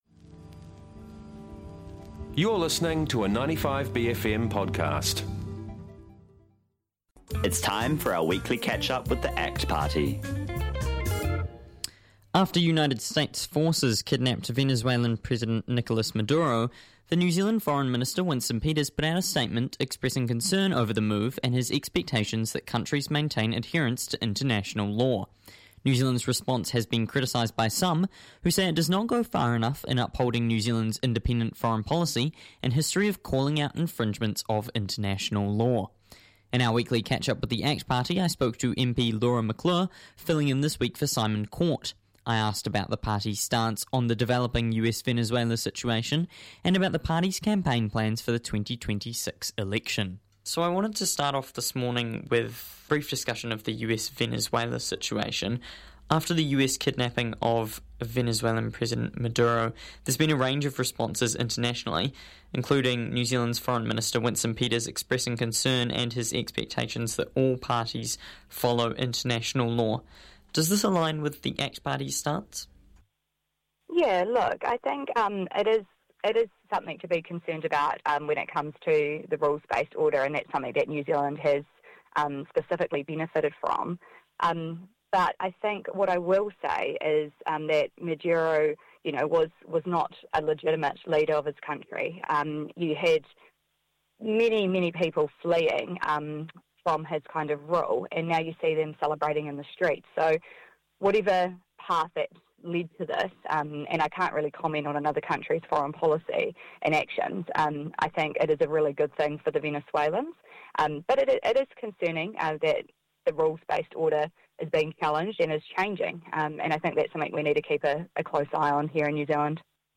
In our weekly catchup with the act party